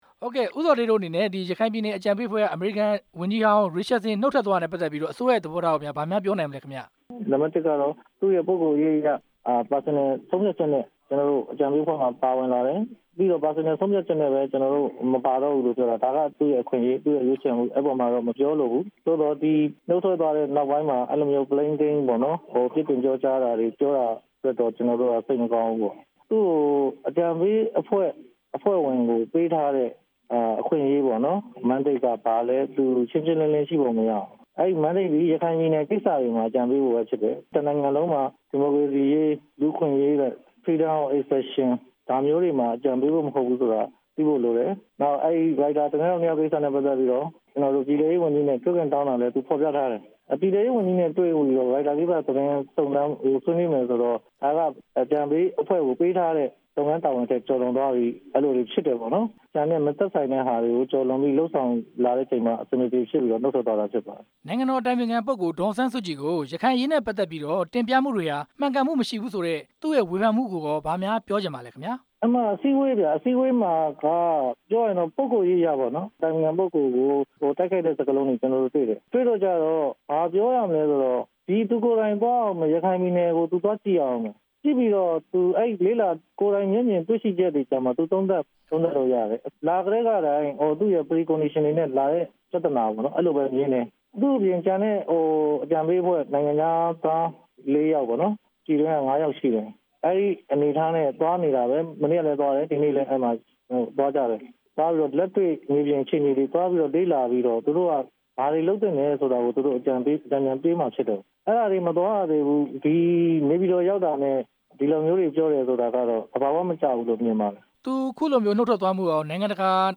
ဘီလ် ရစ်ချတ်ဆင် နုတ်ထွက်တဲ့အကြောင်း ဦးဇော်ဌေးနဲ့ မေးမြန်းချက်